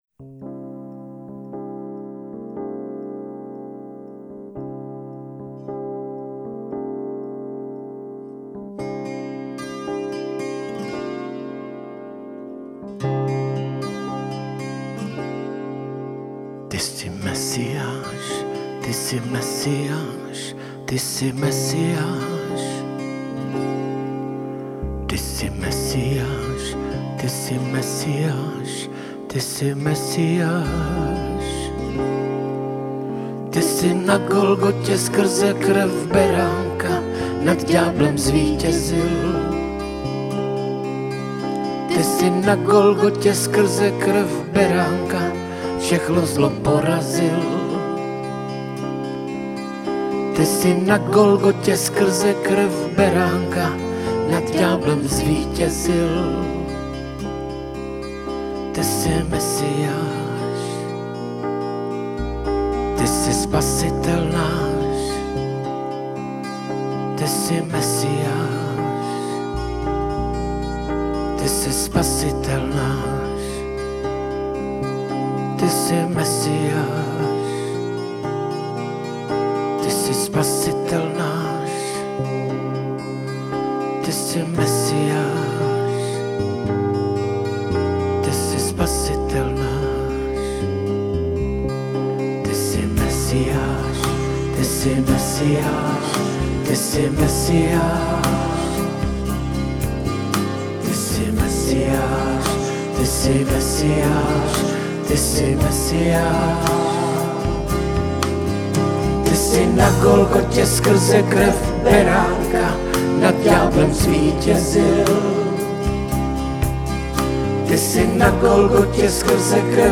Písně ke chvále a uctívání